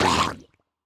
Minecraft Version Minecraft Version latest Latest Release | Latest Snapshot latest / assets / minecraft / sounds / mob / drowned / water / hurt3.ogg Compare With Compare With Latest Release | Latest Snapshot
hurt3.ogg